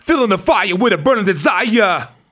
RAPHRASE07.wav